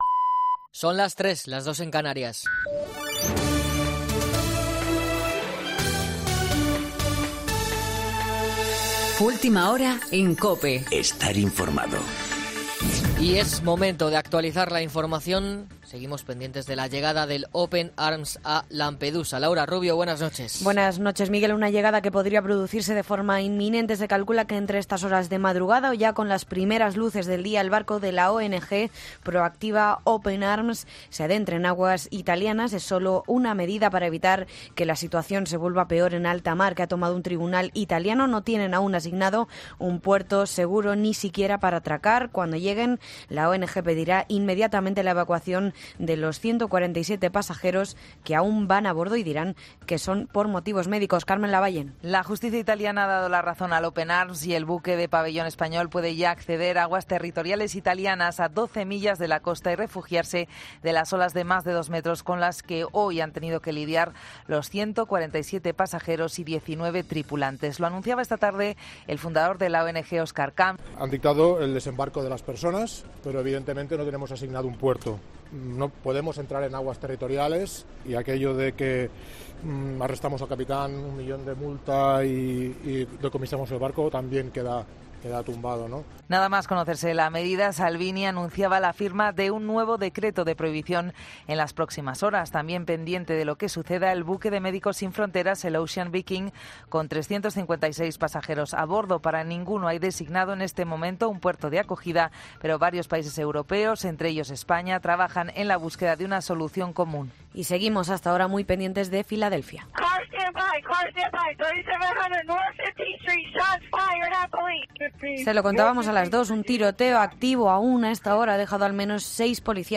Boletín de noticias COPE del 15 de agosto de 2019 a las 03.00 horas